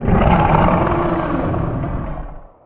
walk_1.wav